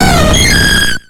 Cri de Mentali dans Pokémon X et Y.